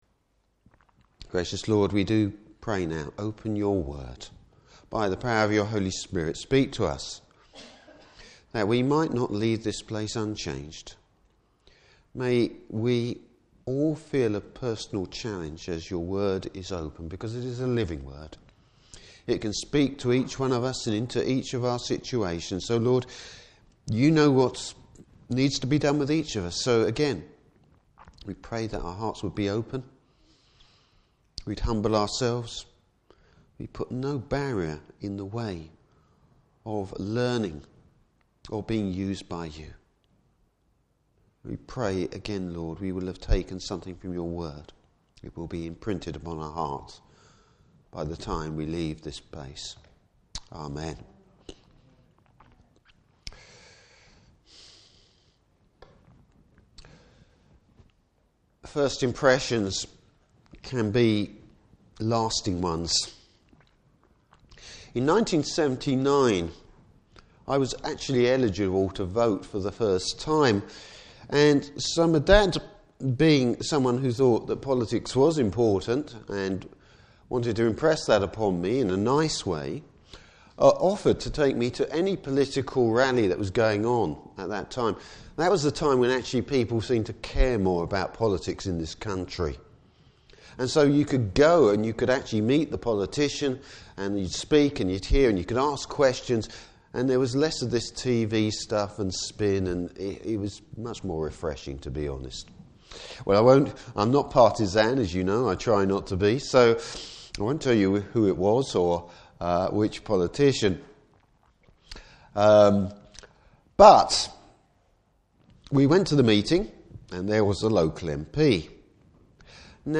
Service Type: Morning Service Bible Text: Matthew 8:18-34.